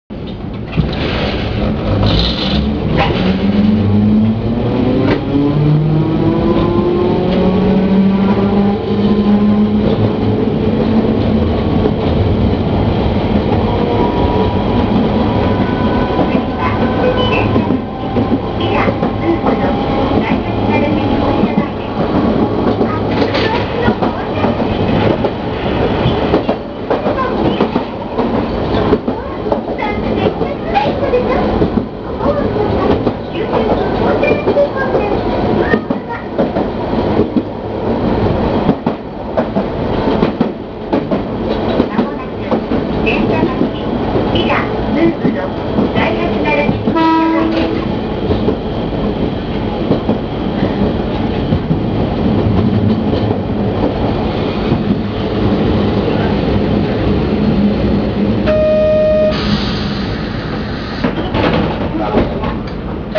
〜車両の音〜
・360形走行音
【本線】宝町〜銭座町（1分3秒：344KB）
ごく普通の吊り掛け式です。